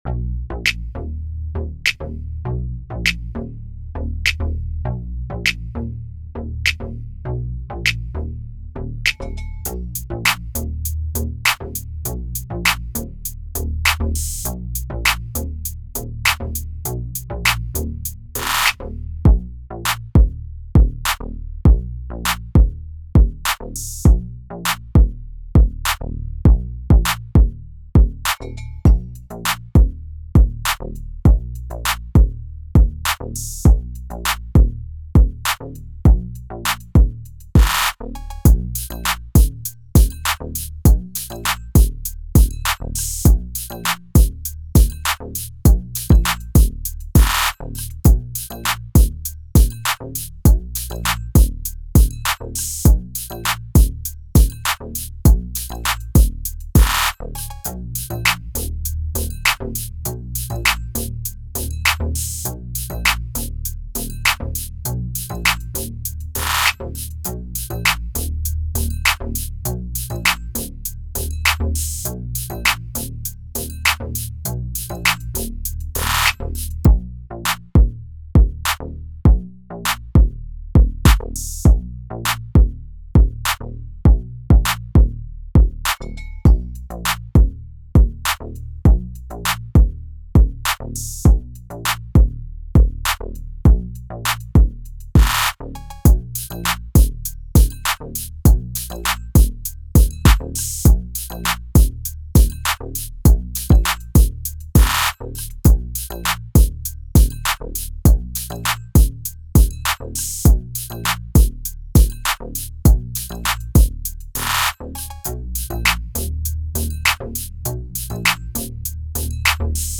Hip Hop
Eb Minor